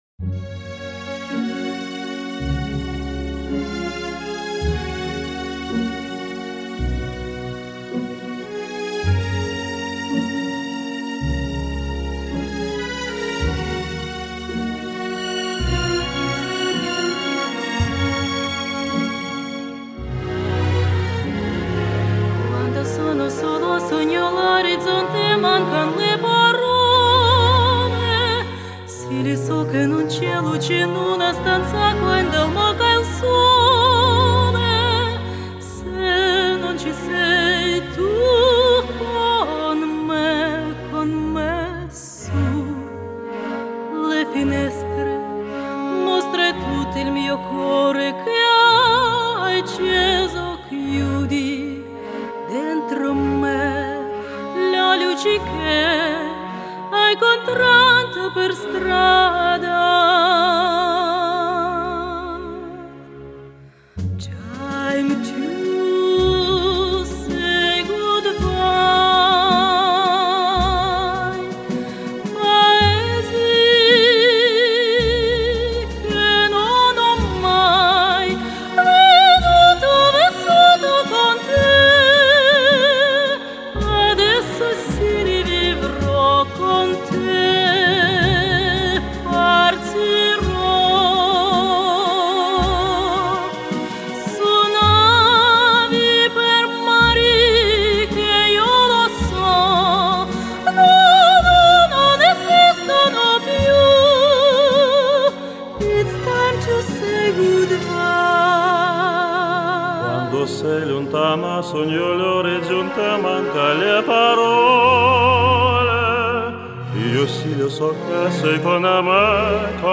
ЖИВОЙ ЗВУК